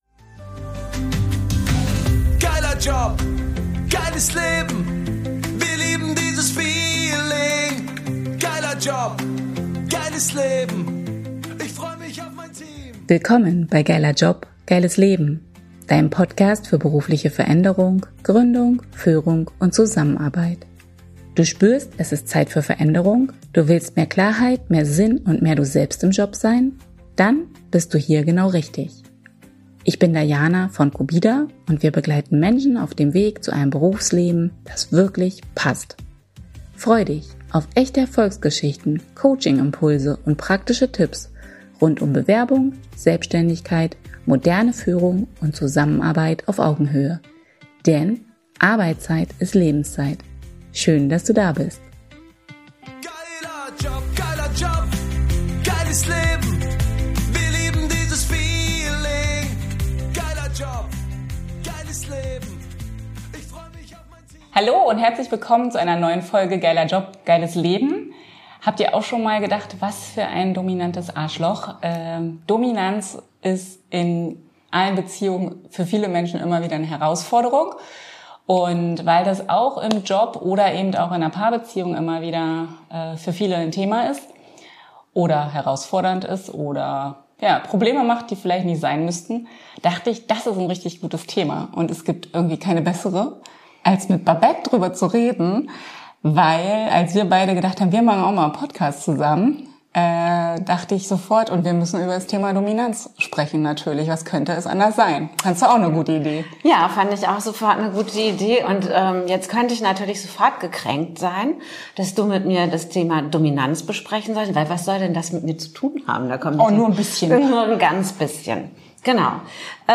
#016 Tanz mit der Dominanz | Interview